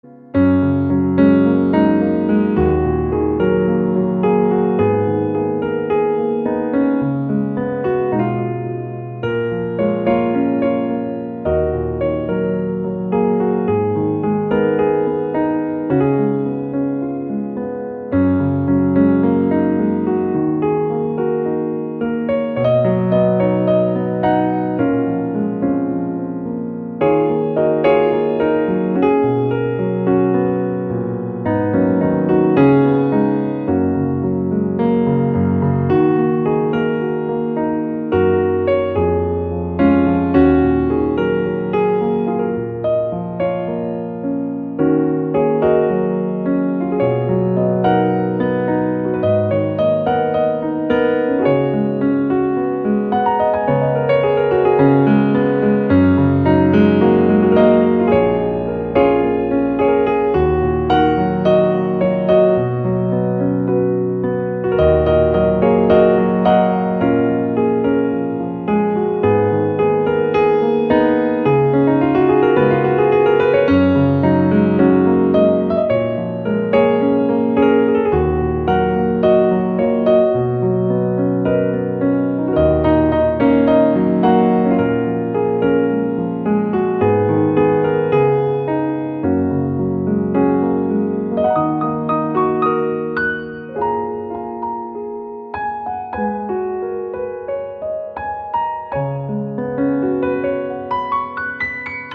Giai điệu piano nhẹ nhàng từ mối tình kinh điển.